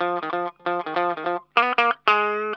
LO-FI 2.wav